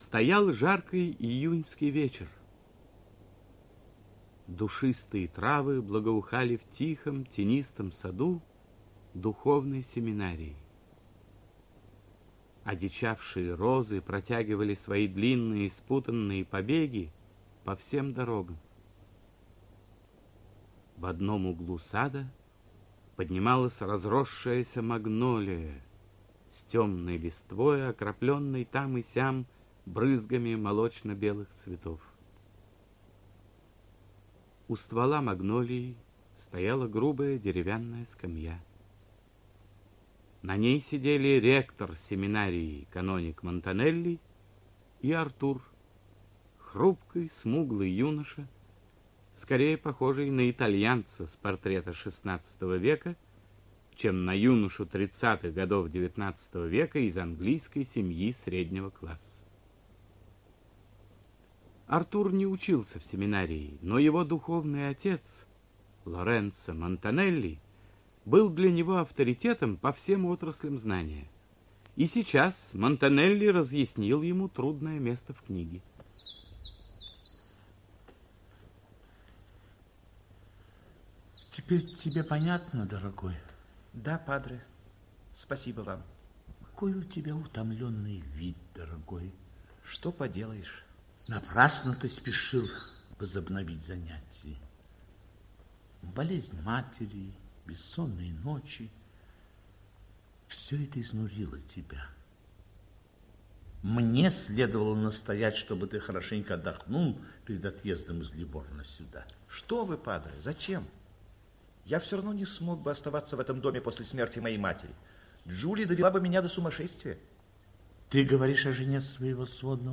Овод - аудио роман Войнич - Роман про революционера